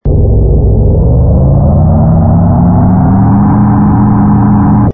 engine_ku_freighter_launch.wav